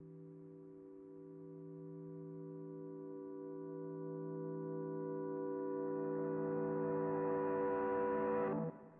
描述：模拟声音，吉他操作
Tag: 吉他 合成器